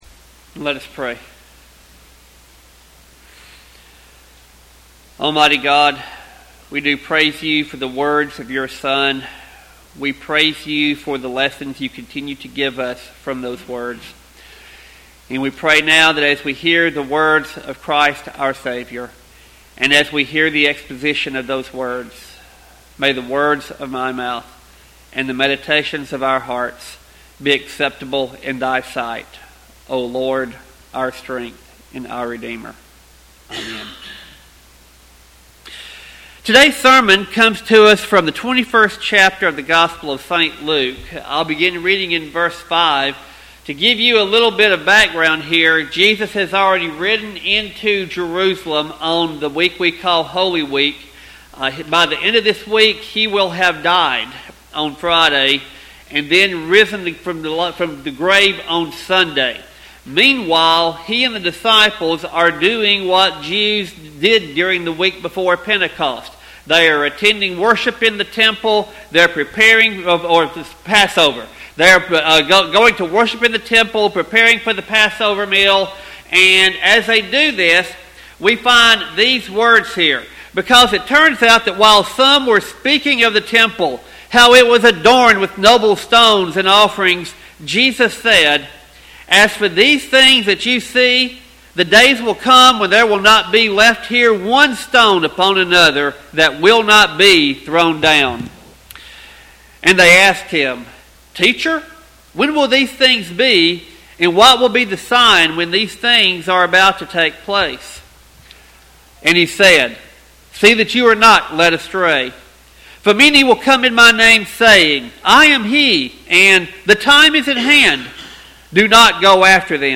Sermon text: Luke 21:5-19.